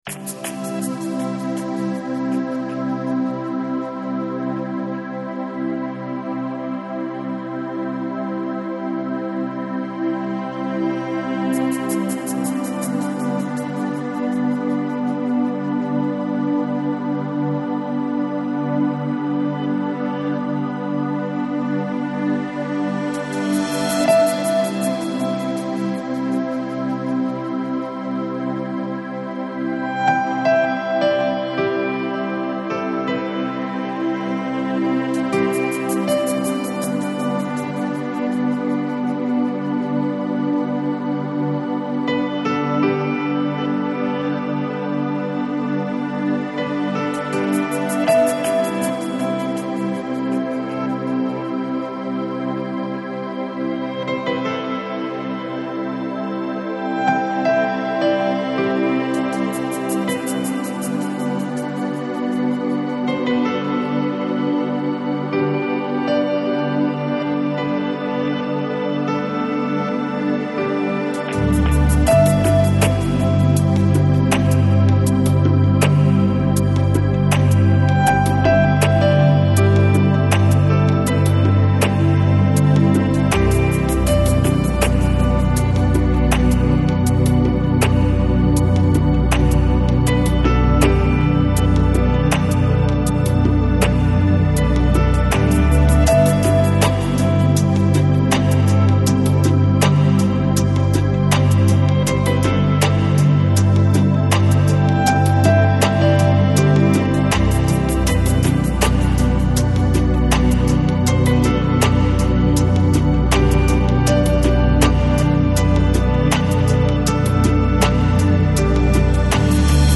Downtempo, Lounge, Chillout, Ambient Носитель